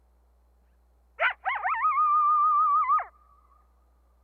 The way they sound : In comparison to wolves, coyotes’ vocalizations are higher-pitched and shorter.
IWC-Coyote-howl.wav